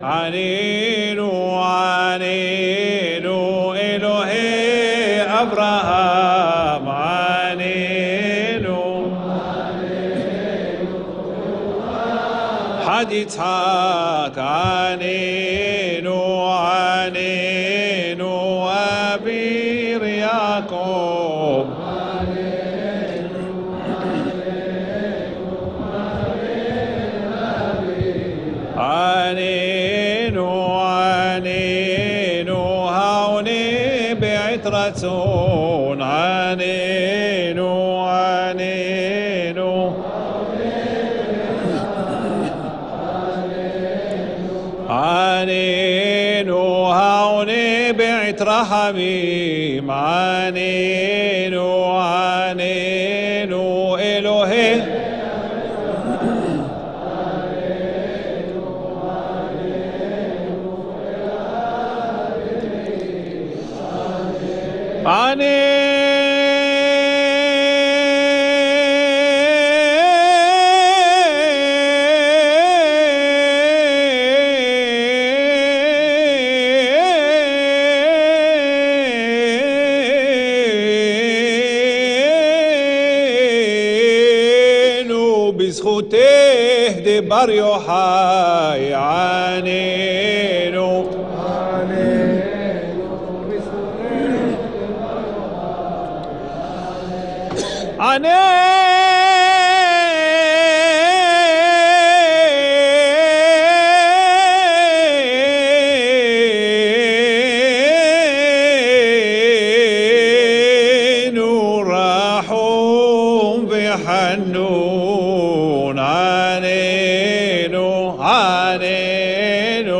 Sephardic minhag Aram Soba - Learn with proper taamim